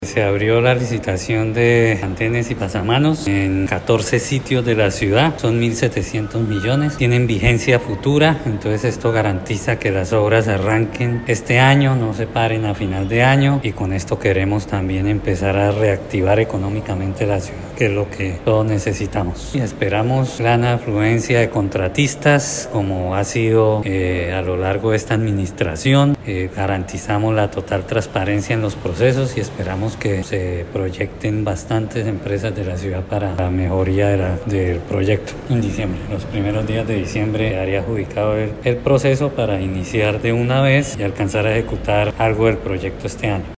Descargue audio: Iván Vargas, secretario de Infraestructura de Bucaramanga